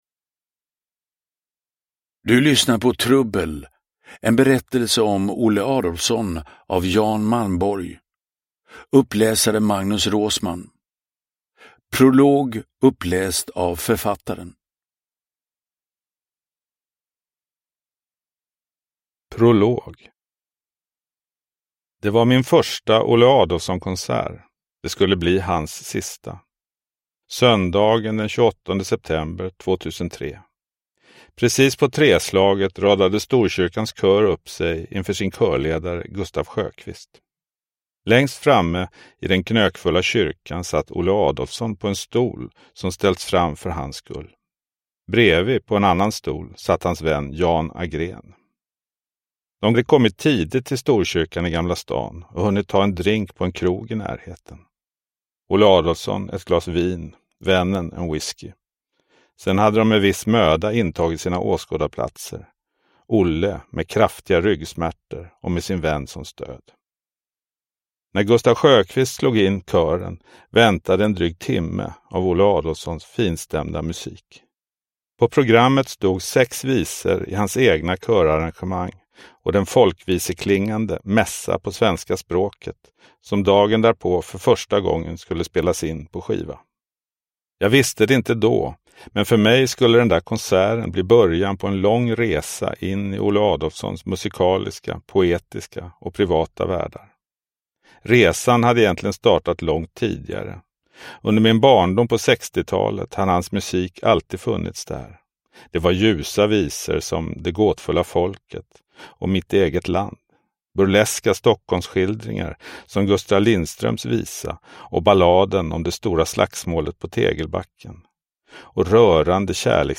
Biografin ”Trubbel” berättar om trubaduren Olle Adolphsons händelserika liv i dur och moll. Texten till ljudboksutgåvan är bearbetad och något förkortad.
Uppläsare: Magnus Roosmann